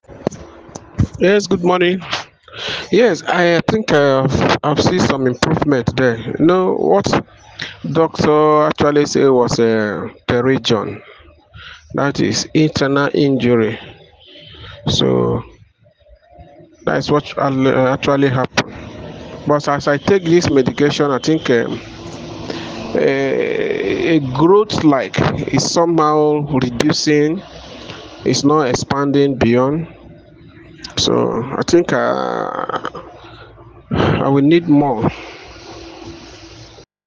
Verified Customer
Testimonial 2